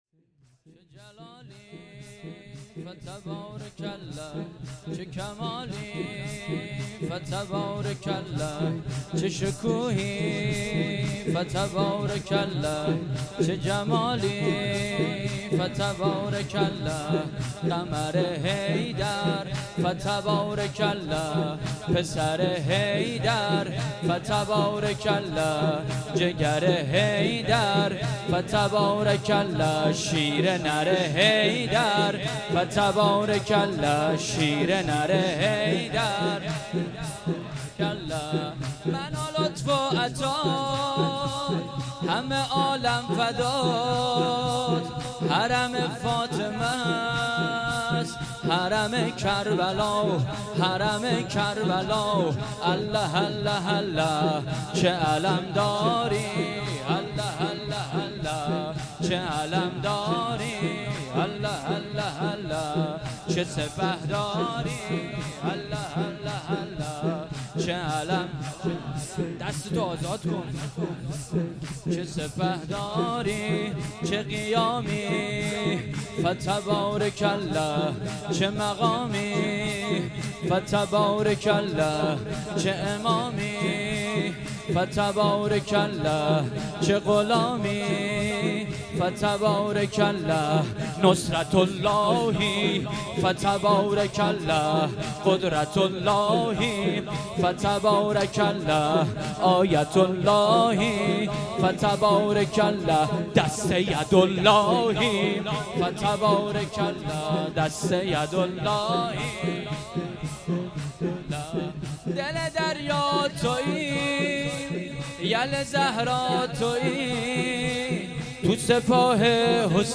هیات انصارالمهدی (عج) بندرامام خمینی (ره)
شب نهم محرم 1398